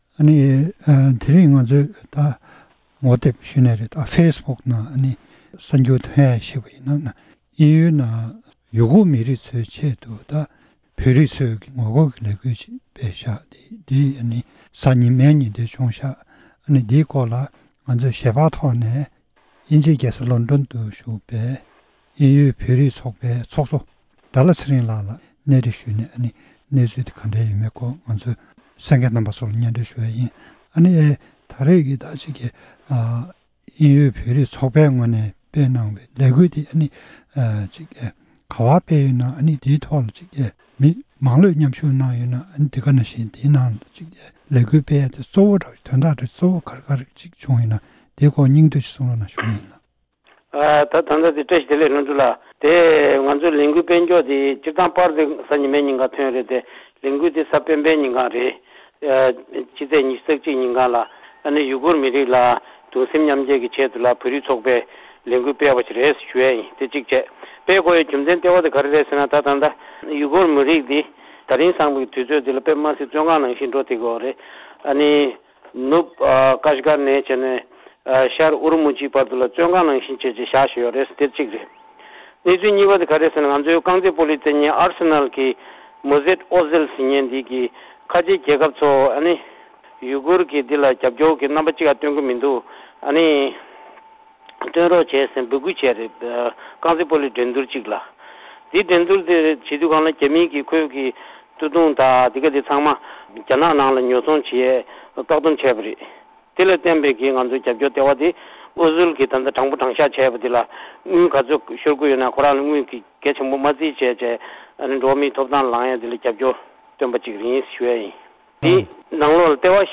གནས་འདྲི་ཞུས་པ་ཞིག་གཤམ་ལ་གསན་རོགས་གནང་།